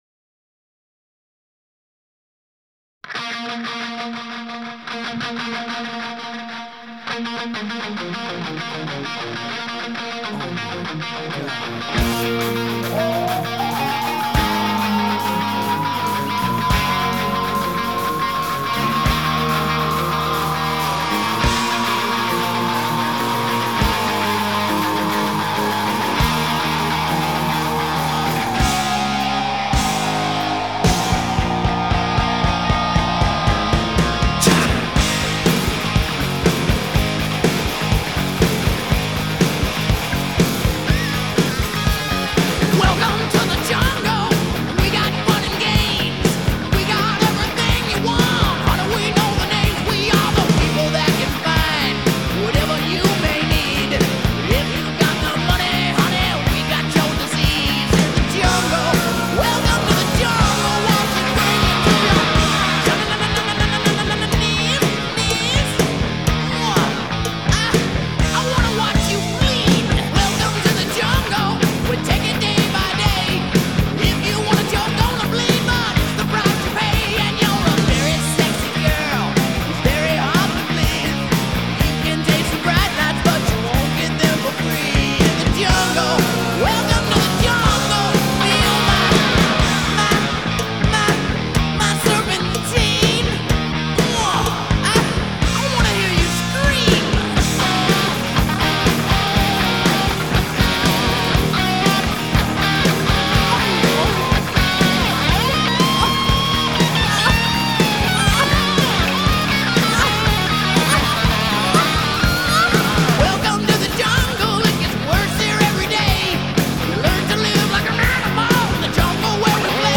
Hard Rock, Glam Rock